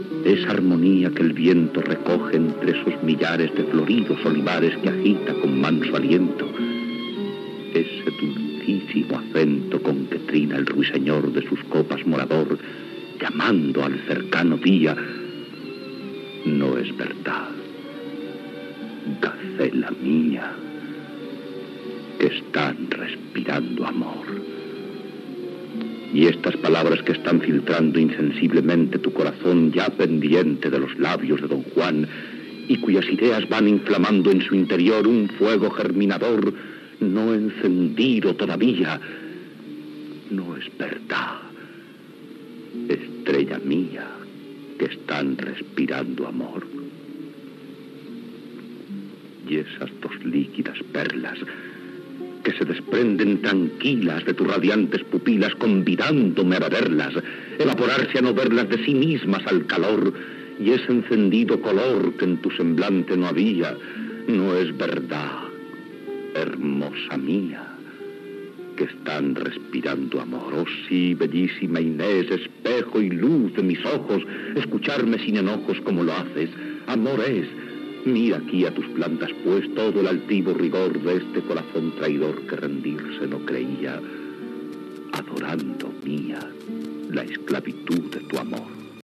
Ficció